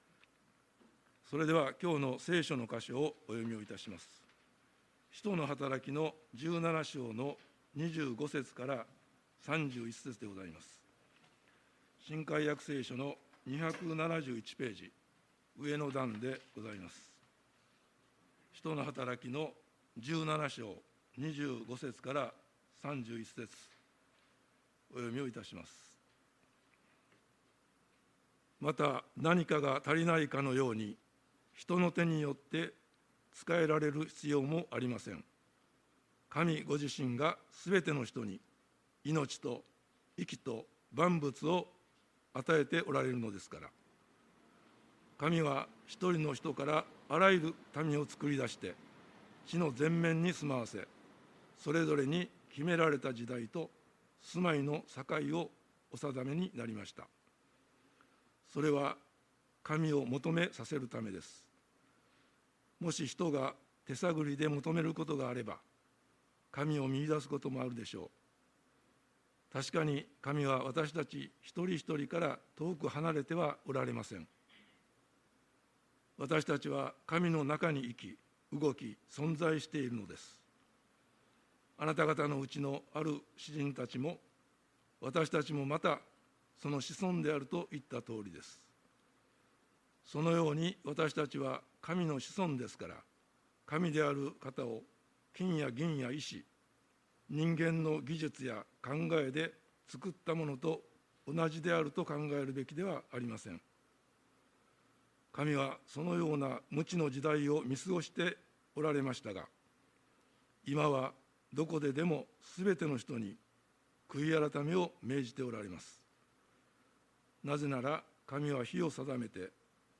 礼拝メッセージ「神への悔い改め」│日本イエス・キリスト教団 柏 原 教 会